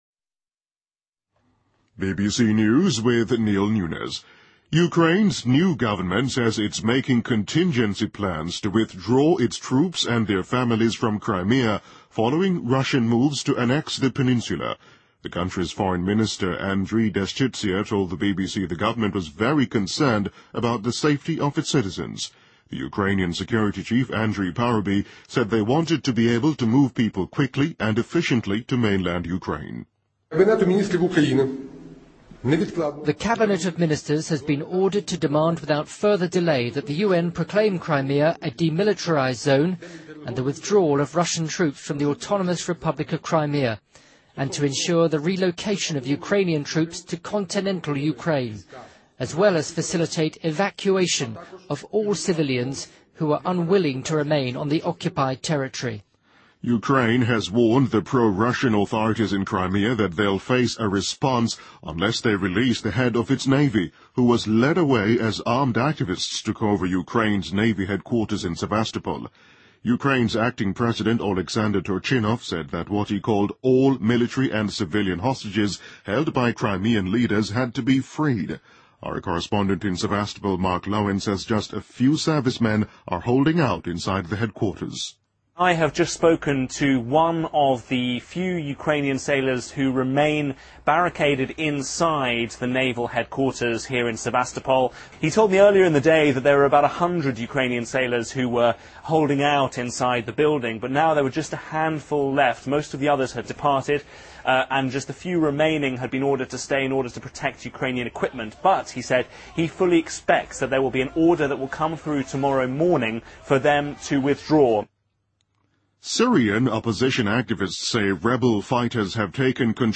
BBC news,2014-03-20